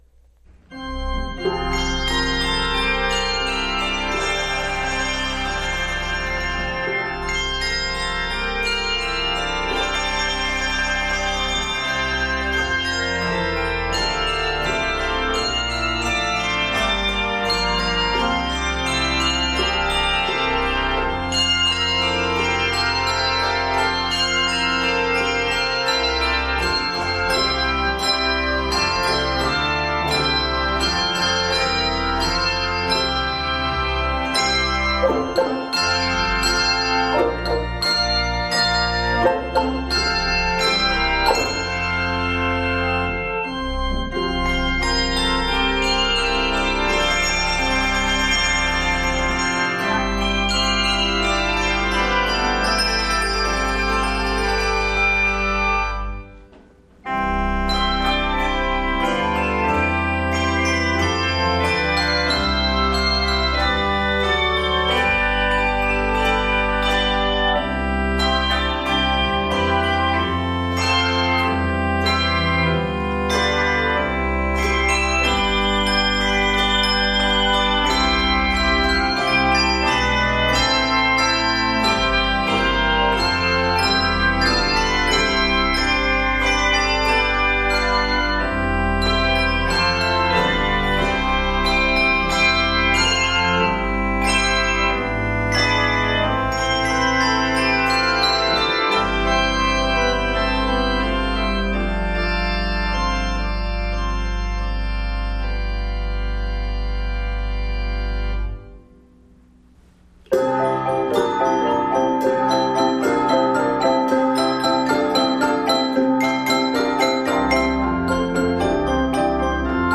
this festive and joyful setting